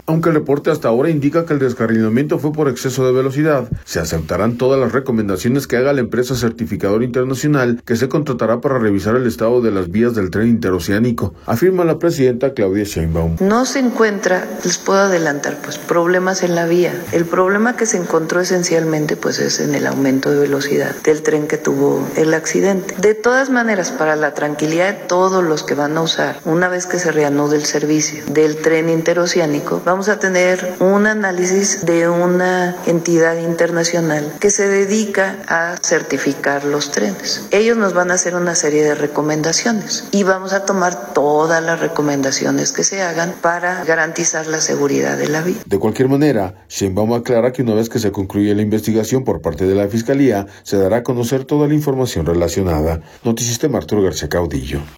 audio Aunque el reporte hasta ahora indica que el descarrilamiento fue por exceso de velocidad, se aceptarán todas las recomendaciones que haga la empresa certificadora internacional que se contratará para revisar el estado de las vías del Tren Interoceánico, afirma la presidenta Claudia Sheinbaum.